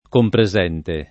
compresente
vai all'elenco alfabetico delle voci ingrandisci il carattere 100% rimpicciolisci il carattere stampa invia tramite posta elettronica codividi su Facebook compresente [ kompre @$ nte ] (meno bene copresente [ kopre @$ nte ]) agg.